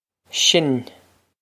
Sin Sh-in
This is an approximate phonetic pronunciation of the phrase.